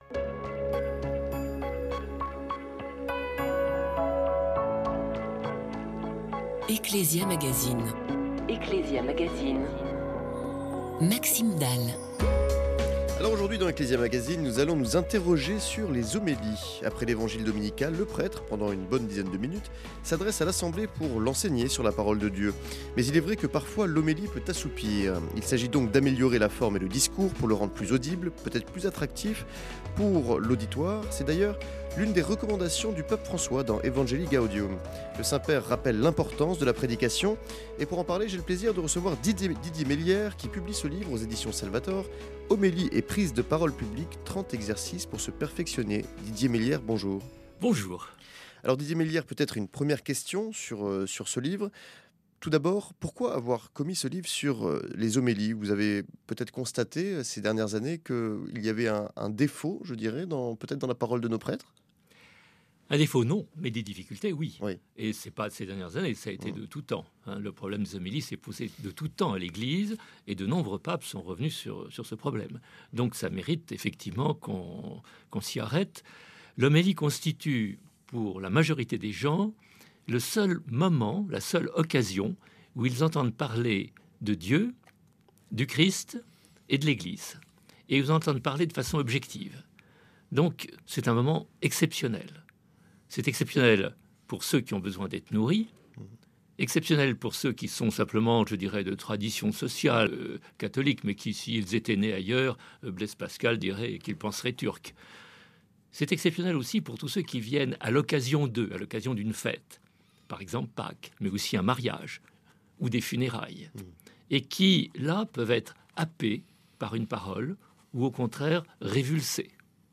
interv-rnd-livre-hp3-10-18.mp3